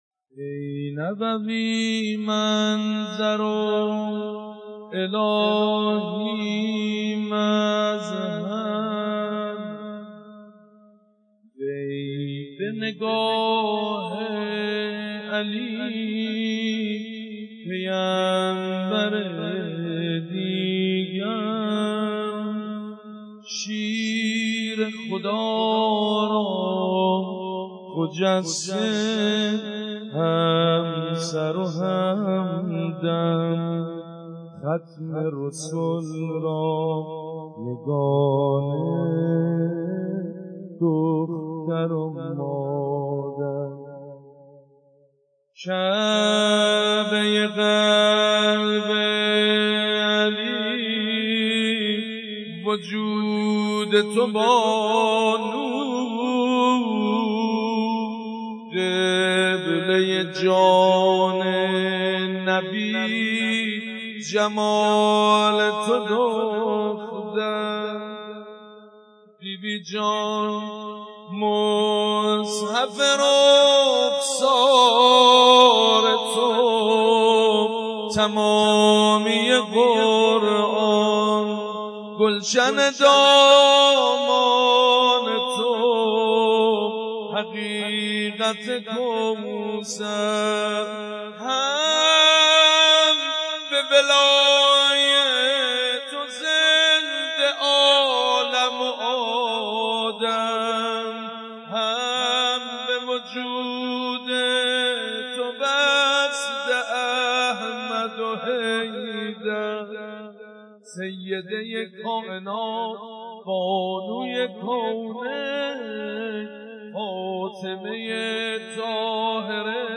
ذکر مصیبت شهادت مظلومانه حضرت زهرا(س